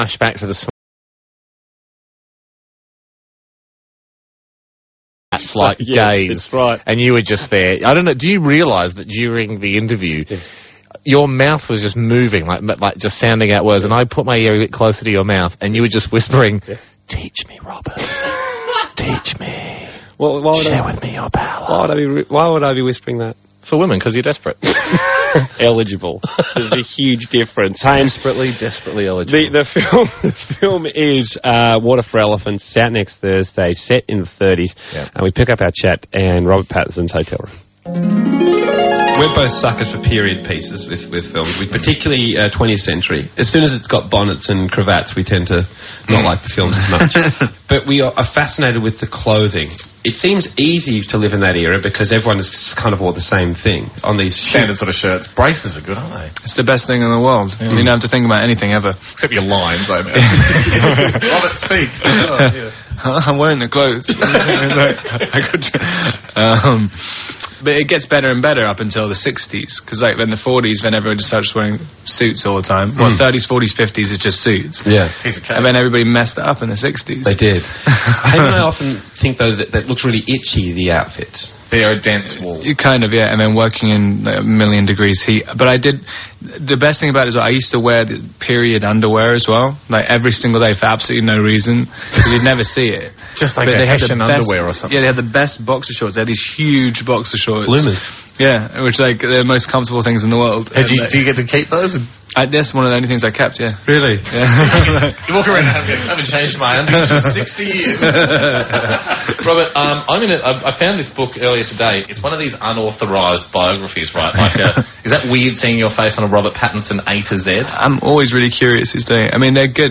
*AUDIO and VIDEO* Robert Pattinson Interview with 91.9 Sea FM   3 comments
Here’s a radio interview with Rob with Hamish & Andy on Australian Radio